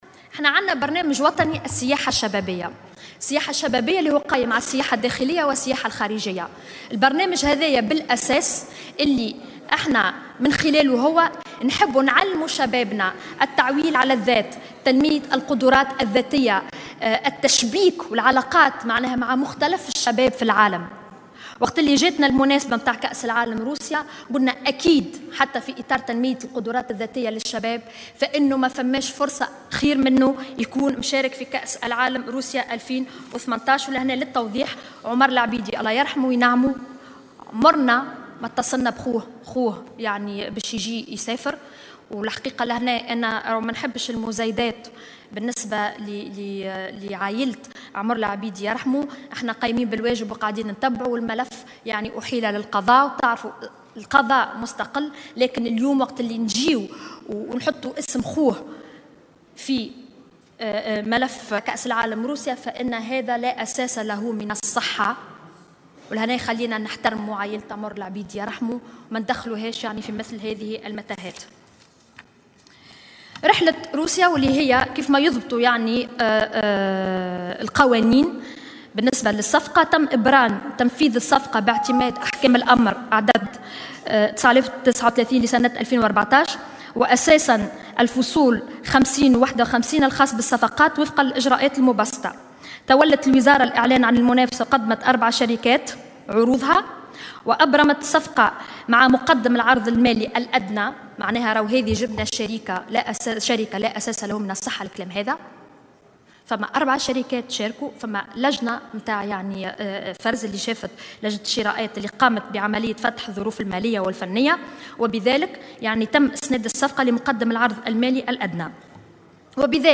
أكدت وزيرة شؤون الشباب و الرياضة ماجدولين الشارني أن خلال جلسة المساءلة اليوم الجمعة 06 جويلية 2018 أمام مجلس نواب الشعب أن السفرة التي تم تنظيمها ل24 شابا إلى روسيا لحضور مقابلات المنتخب التونسي في كأس العالم تدخل في إطار البرنامج الوطني للسياحة الشبابية و أنه تم فتح تحقيق جدي في الإخلالات الواقعة من قبل الشركة التي أوكلت إليها مسألة التنظيم.